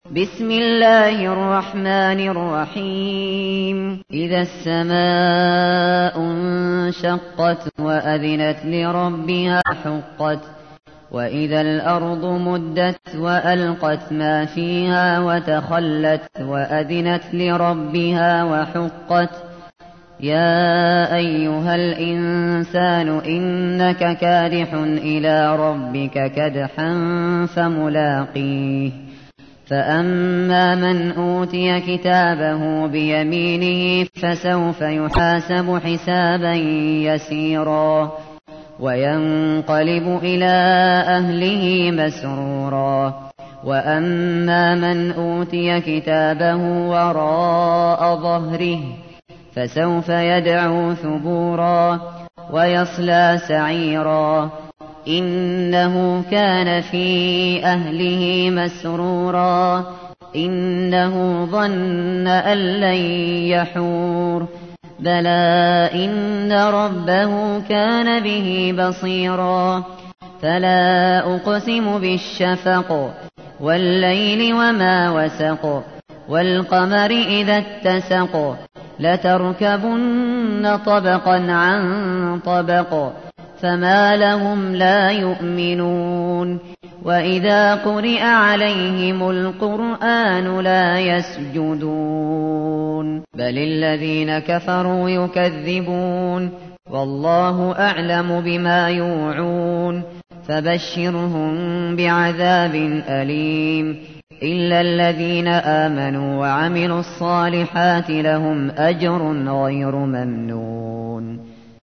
تحميل : 84. سورة الانشقاق / القارئ الشاطري / القرآن الكريم / موقع يا حسين